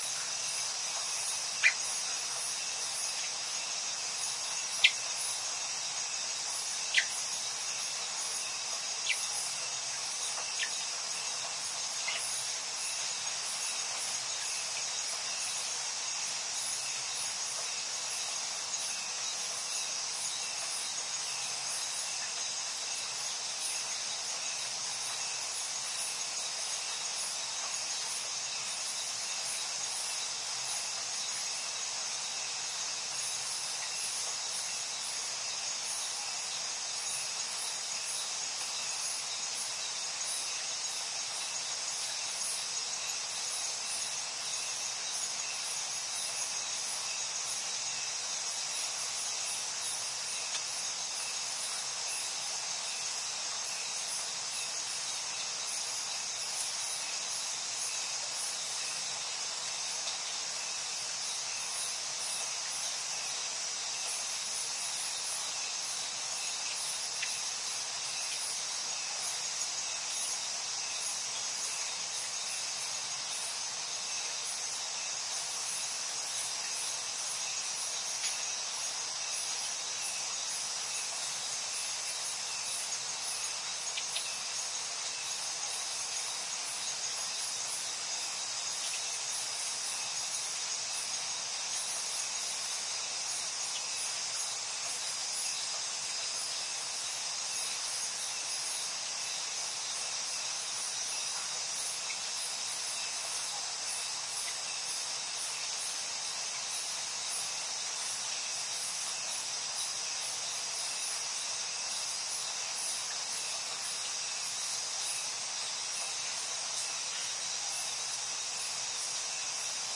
泰国 " 泰国丛林夜色中的蟋蟀，来自竹屋内的蟋蟀+鸟叫声夜色中的声音
描述：泰国丛林的夜晚，竹屋内传来沉重的蟋蟀声+鸟叫声的夜晚音
Tag: 泰国 晚上 丛林 蟋蟀 小屋 现场录音